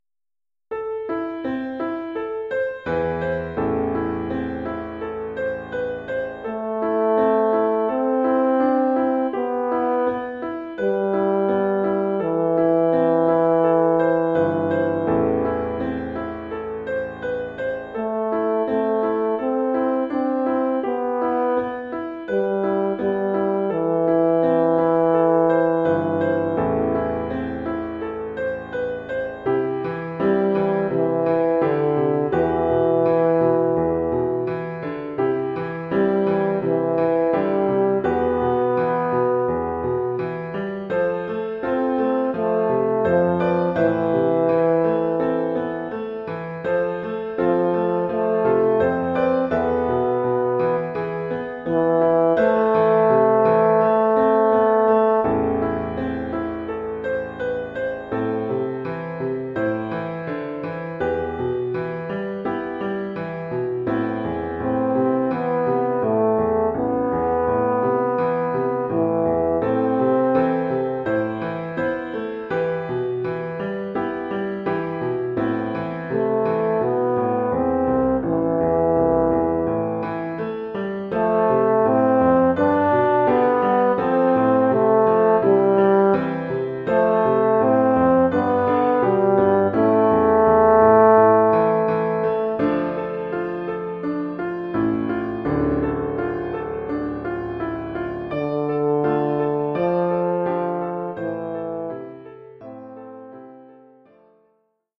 Formule instrumentale : Cor et piano
Oeuvre pour cor d’harmonie et piano.